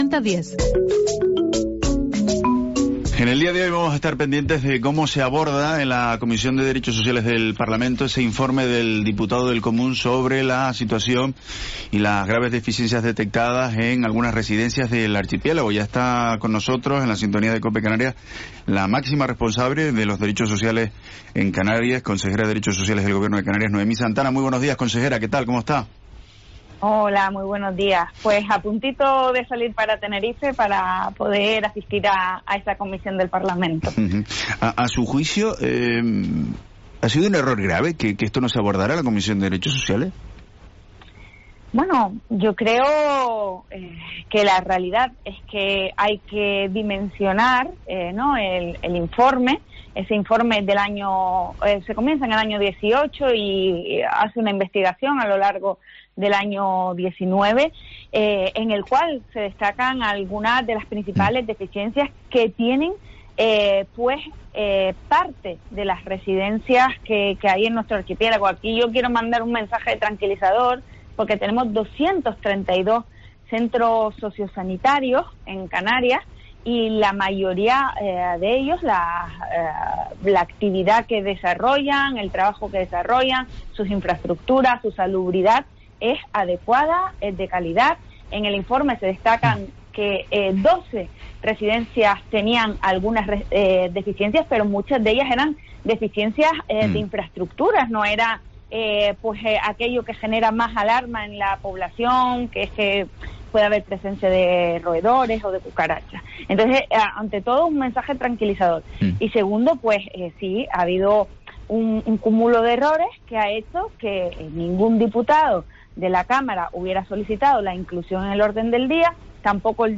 Entrevista a la consejera de Derechos Sociales, Noemí Santana (14/9/21)